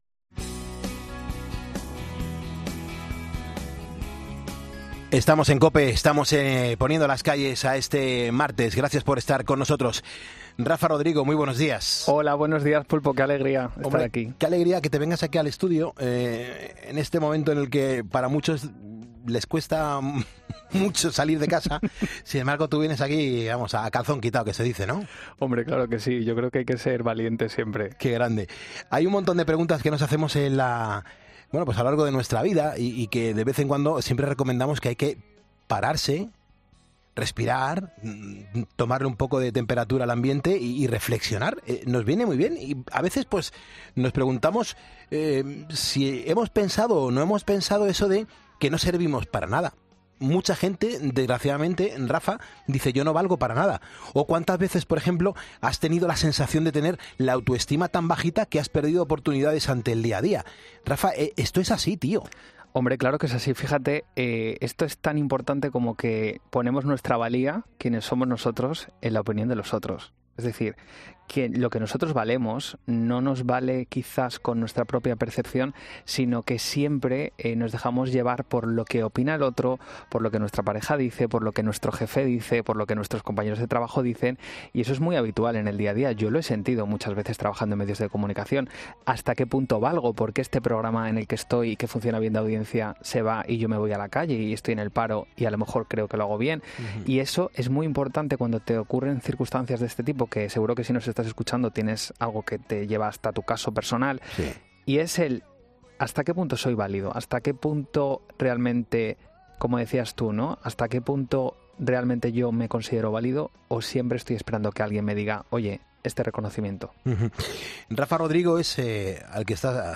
se ha acercado hasta los estudios centrales de COPE